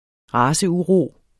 Udtale [ ˈʁɑːsəuˌʁoˀ ]